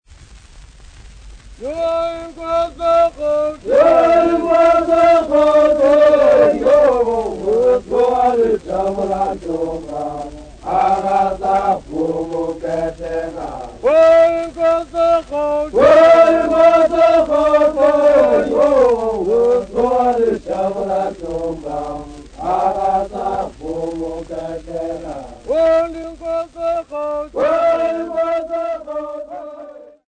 A recording of an unaccompanied traditional song by Hlubi men. This song was recorded at an unspecified location field recording, 12/09/1948.]